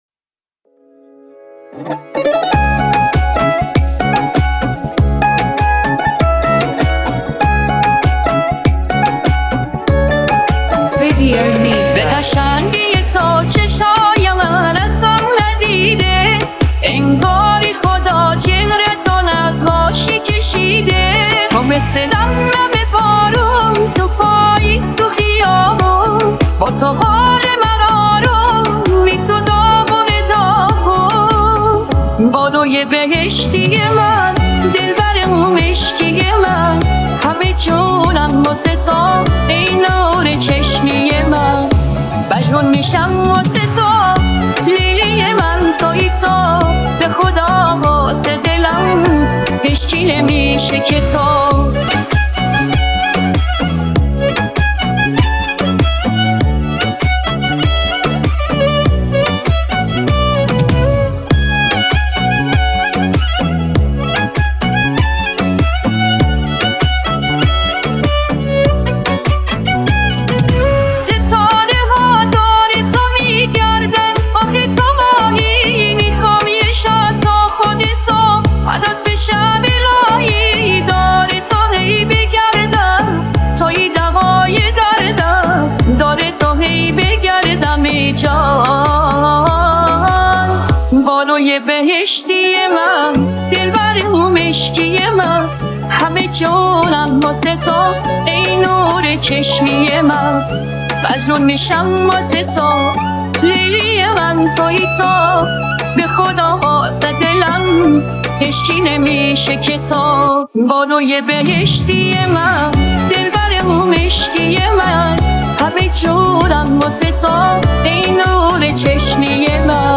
دانلود آهنگ پاپ ایرانی
دانلود آهنگ های هوش مصنوعی